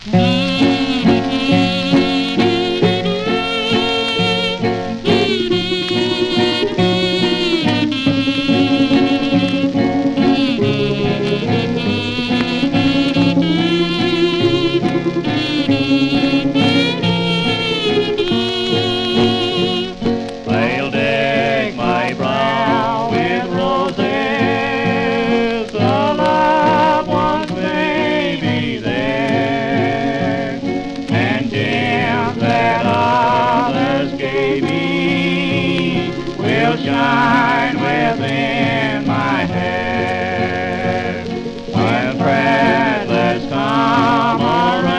バンジョー
ギター＆カズー
Blues, Folk, World, & Country　Germany　12inchレコード　33rpm　Mono